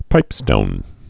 (pīpstōn)